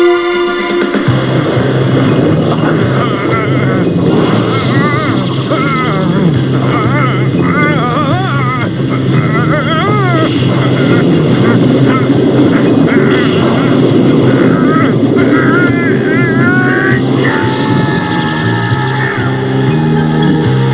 You hear goku turn ss1